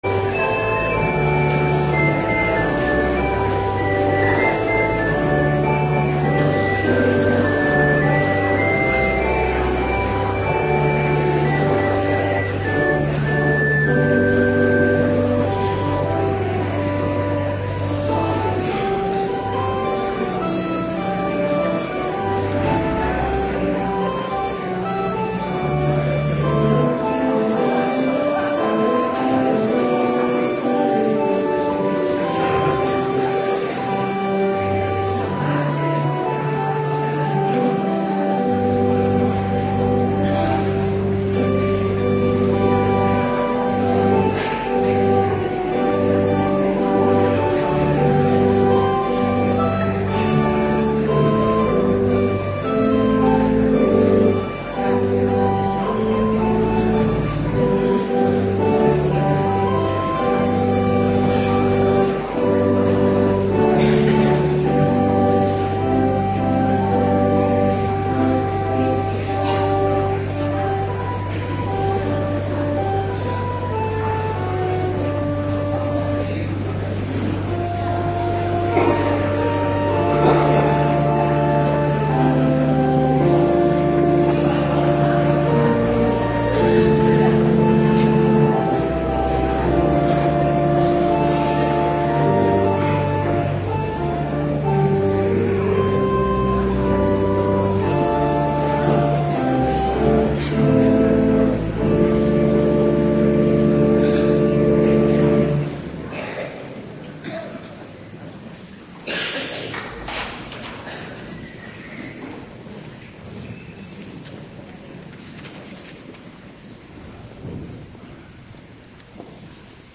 Morgendienst 26 april 2026